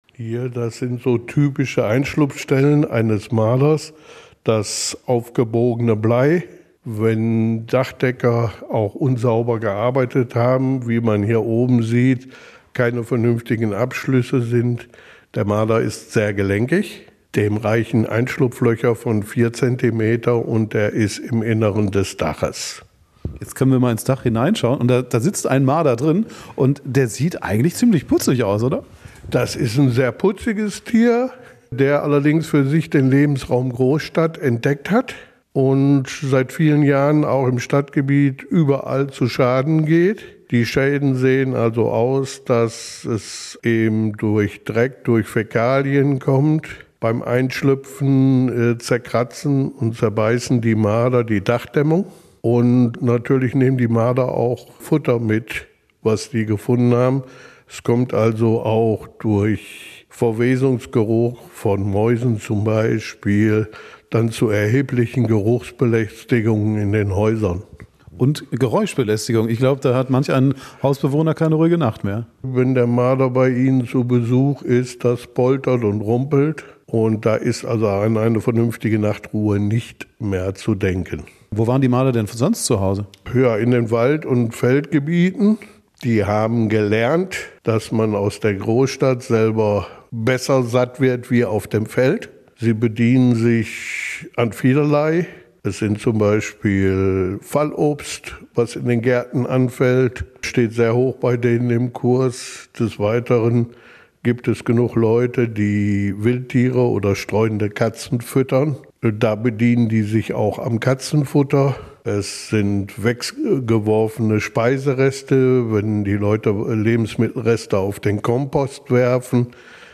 Wir haben mit einem Experten gesprochen.